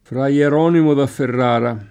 fra Ler0nimo da fferr#ra] (Guicciardini); fra Galdino, quel delle noci [fra ggald&no, kU%l delle ni] (Manzoni) — sempre davanti a cons. o semicons., così in antico come oggi, possibile anche frate per esteso senza tronc.: se lo incominciò frate Puccio a menare talvolta a casa [Se lo ijkomin©0 ffrate p2©©o a mmen#re talv0lta a kk#Sa] (Boccaccio); continuando adunque il monaco a casa di fra Puccio [